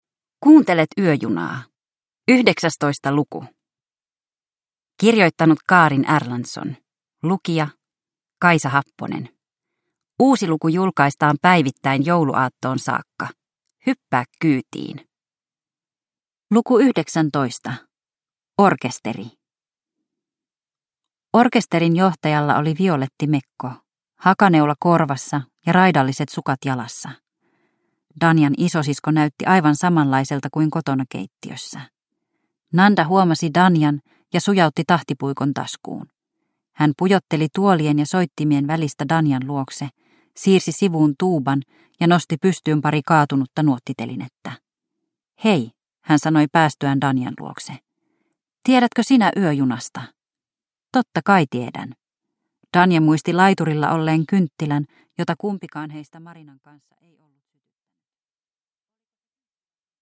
Yöjuna luku 19 – Ljudbok